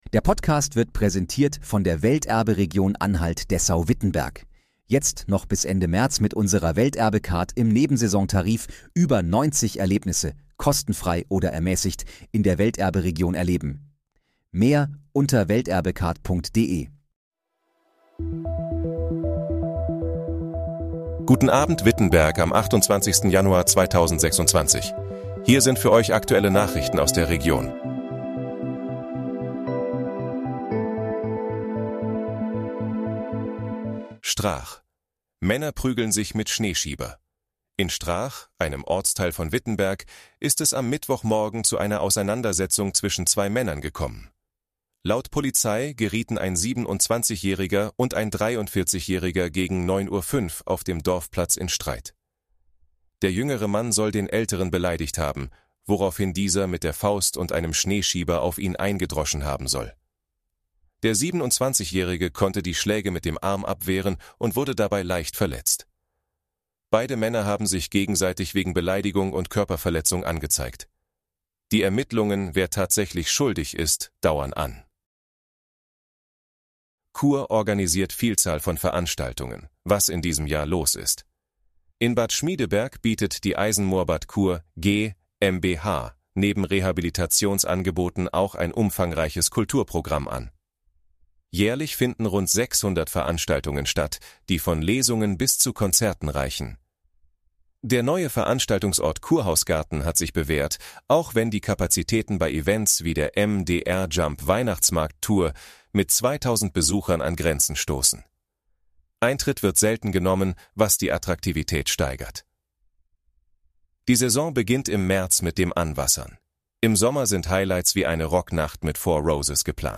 Guten Abend, Wittenberg: Aktuelle Nachrichten vom 28.01.2026, erstellt mit KI-Unterstützung
Nachrichten